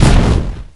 rose_rocket_explo_01.ogg